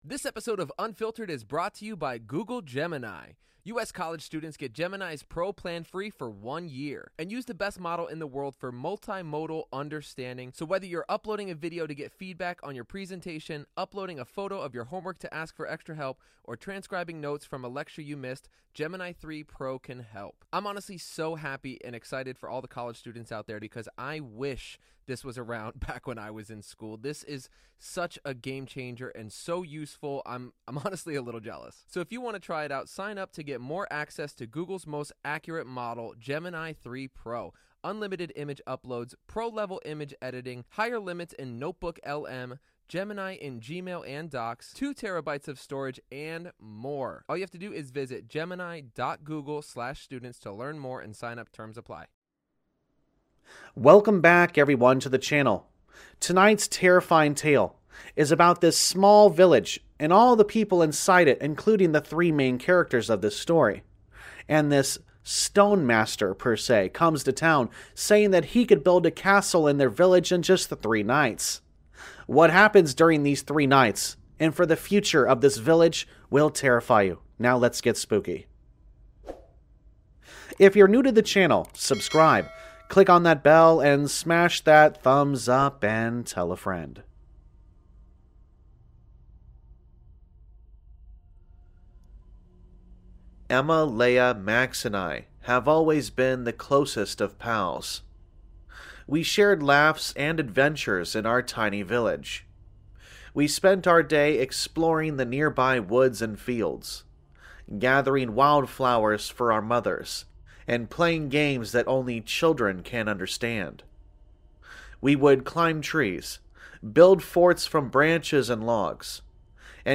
All Stories are read with full permission from the authors: